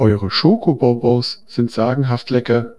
A free to use, offline working, high quality german TTS voice should be available for every project without any license struggling.